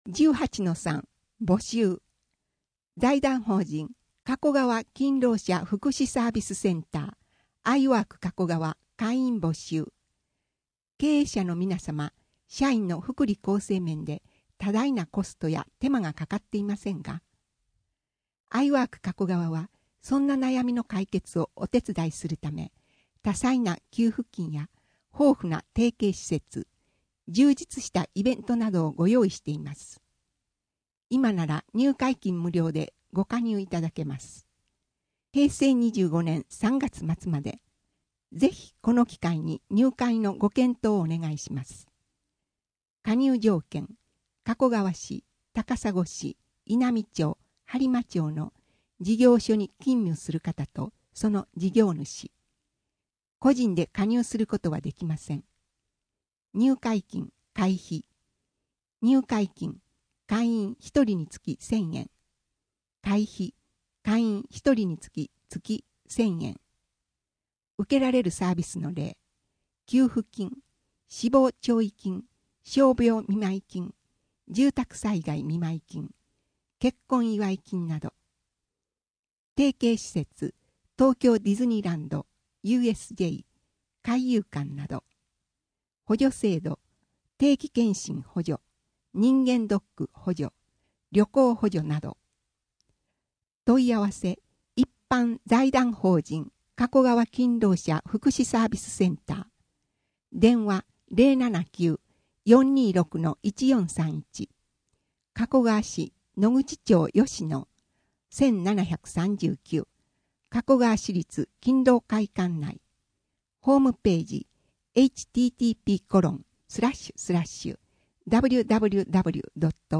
声の「広報はりま」5月号
声の「広報はりま」はボランティアグループ「のぎく」のご協力により作成されています。